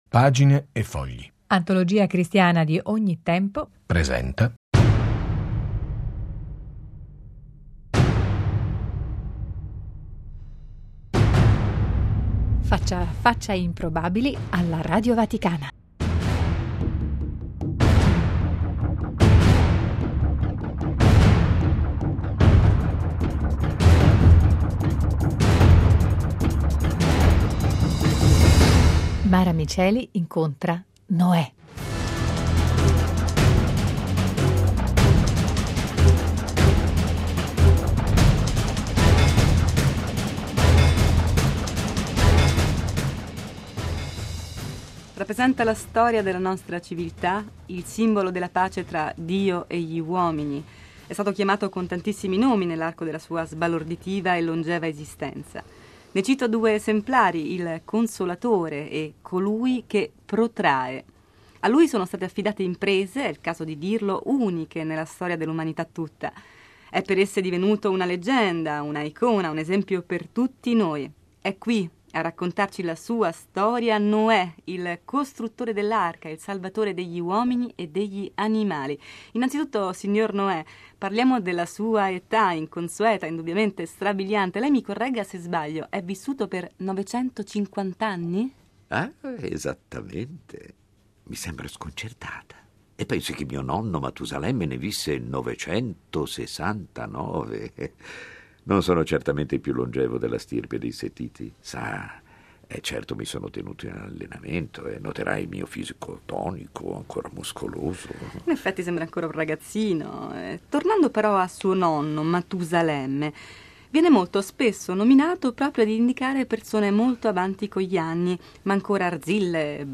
Una personale e divertente lettura dell'episodio Biblico della costruzione dell' Arca di Noè